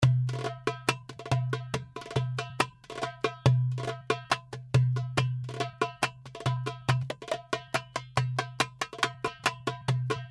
Wahda Darbuka 70 BPM
This package contains real darbuka loops in wahda style.
The darbuka was recorded with vintage neumann u87 in a dry room by a professional Darbuka player. The darbuka you are hearing and downloading is in stereo mode , that means that The darbuka was recorded twice.(beat in the left speaker).
The darbuka is in mix mode( no mastering ,no over compressing).